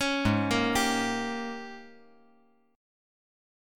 G#M7sus2sus4 Chord
Listen to G#M7sus2sus4 strummed